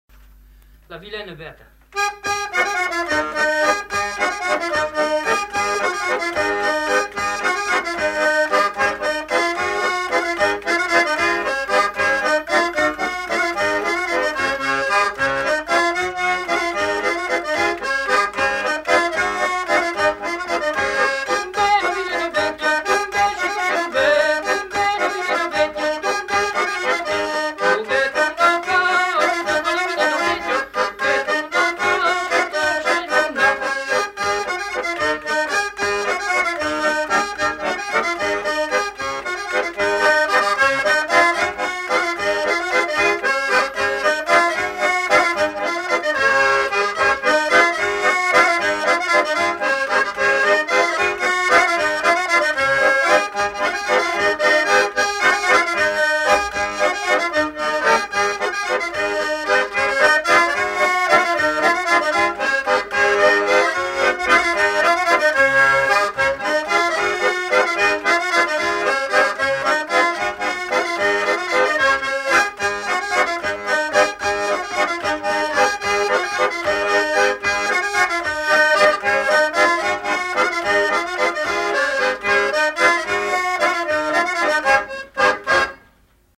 Aire culturelle : Limousin
Genre : chanson-musique
Type de voix : voix d'homme
Production du son : chanté
Instrument de musique : accordéon chromatique
Danse : bourrée